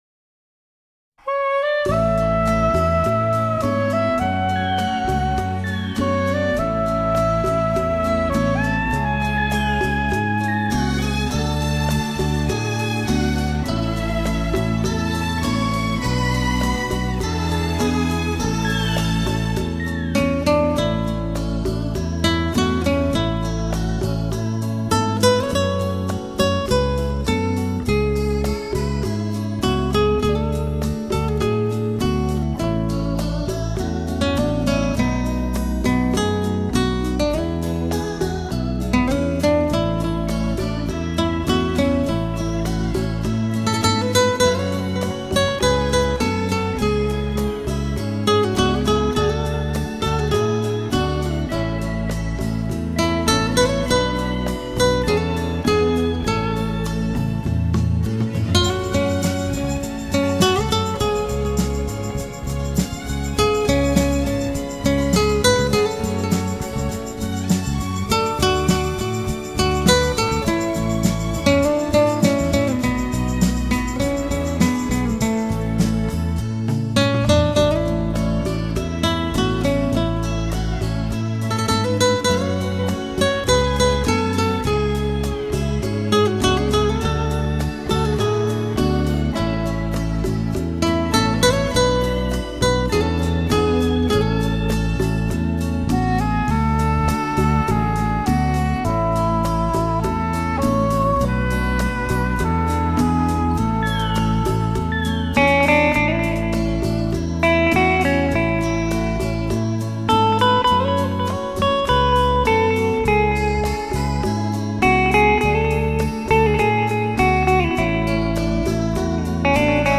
他的拿手好戏是演奏日本民间演歌（动听的传统及现代歌谣）弹奏风格淳厚温和，炉火纯青。